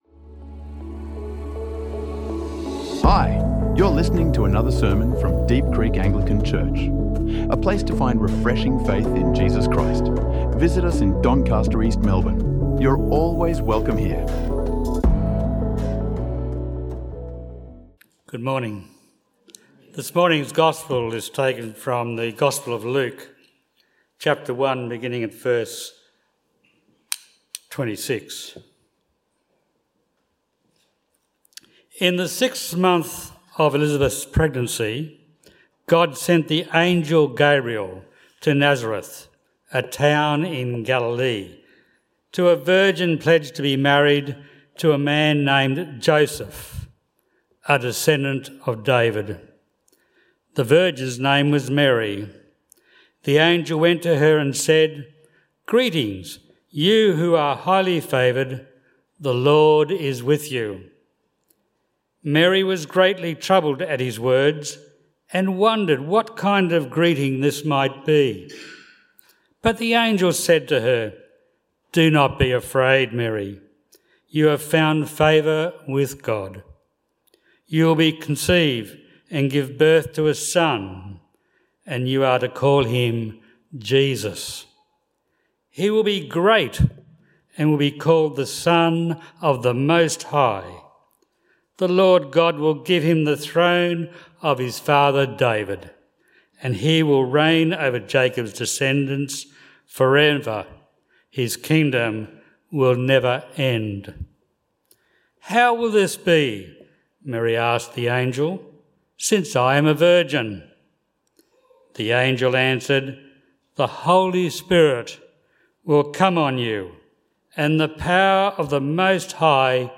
Trusting in Peace | Sermons | Deep Creek Anglican Church